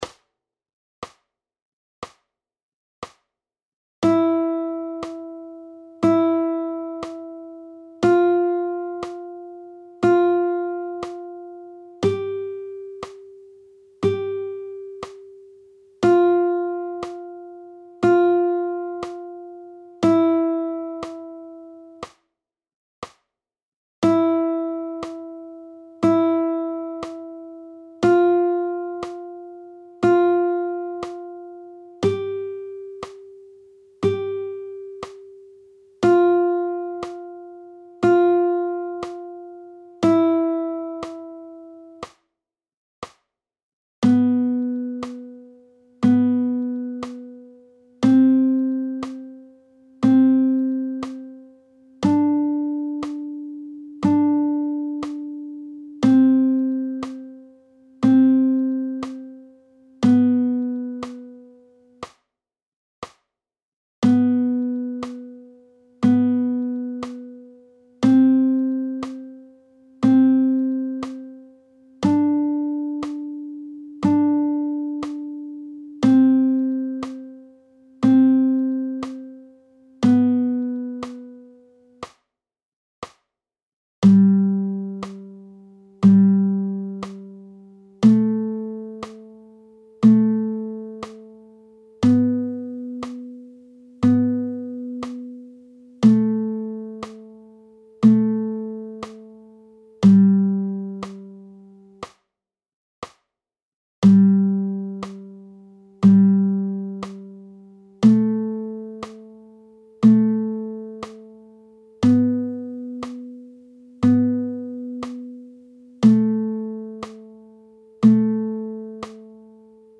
1.) Töne der 1. – 6. Saite